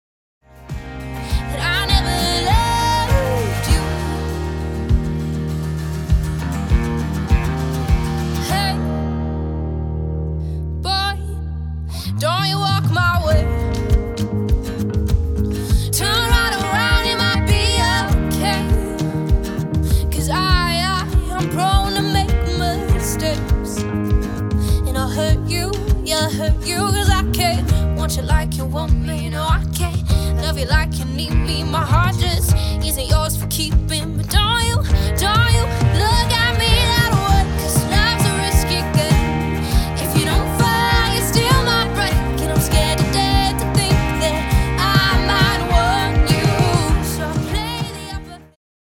Singer Songwriter
Singer-Songwriter.mp3